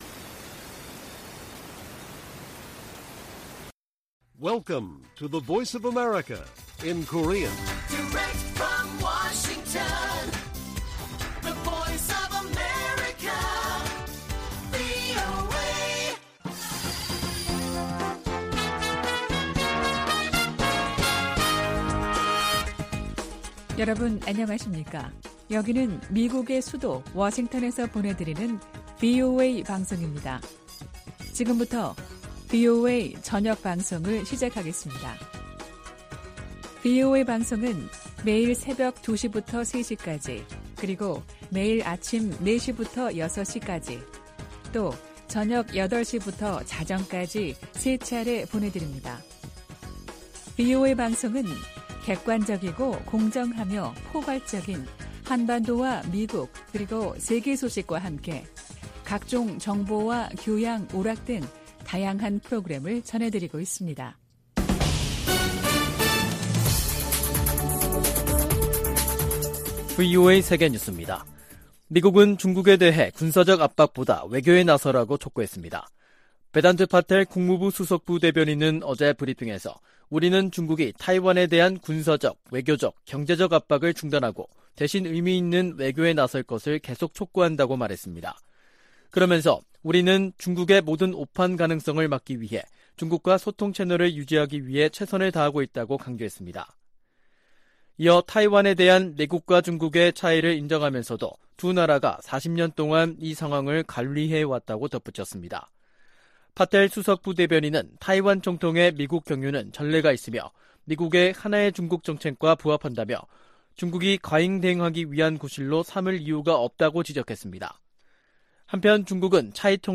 VOA 한국어 간판 뉴스 프로그램 '뉴스 투데이', 2023년 4월 7일 1부 방송입니다. 미한일 북핵 수석대표들이 7일 서울에서 공동성명을 발표하고 유엔 회원국들에 안보리 결의를 완전히 이행할 것을 촉구했습니다. 백악관은 북한이 대화에 복귀하도록 중국이 영향력을 발휘할 것을 촉구했습니다. 북한 정보기술 노동자와 화가 등이 여전히 중국과 동남아시아에서 외화벌이를 하고 있다고 유엔이 지적했습니다.